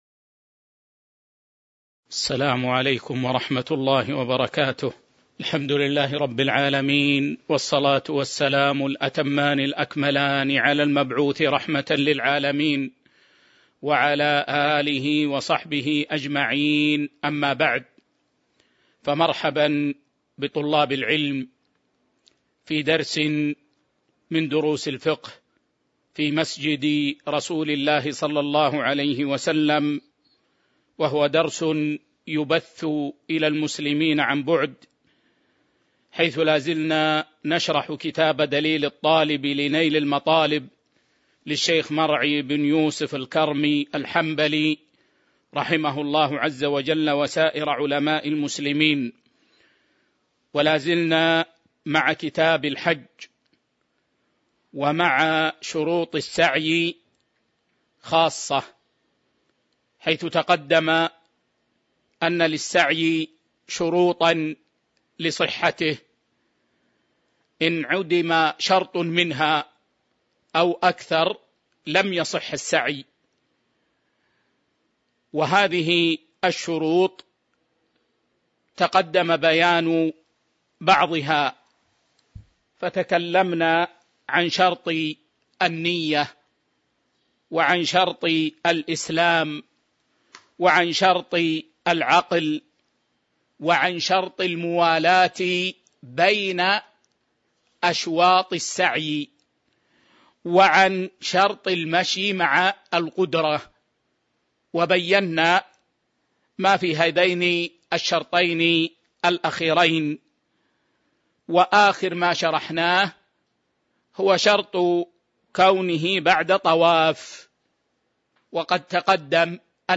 تاريخ النشر ٢٥ ربيع الثاني ١٤٤٢ هـ المكان: المسجد النبوي الشيخ